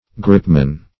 Gripman \Grip"man\, n. The man who manipulates a grip.
gripman.mp3